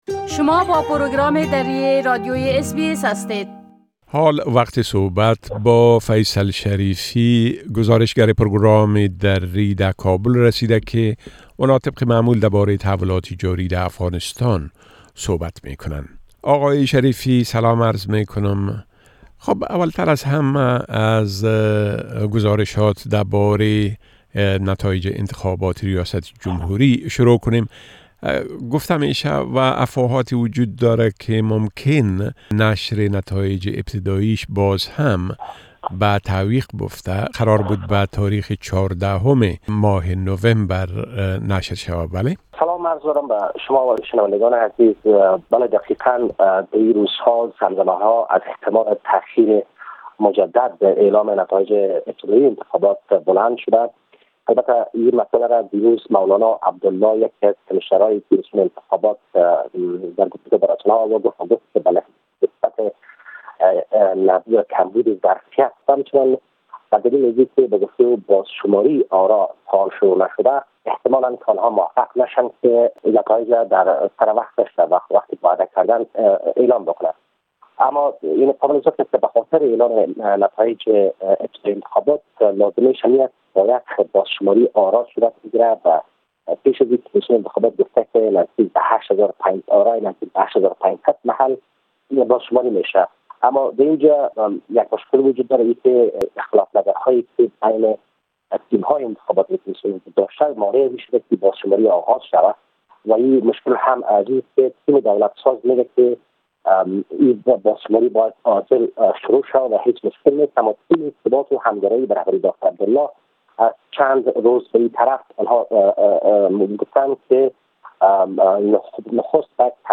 A report from our correspondent in Afghanistan which can be heard here in Dari language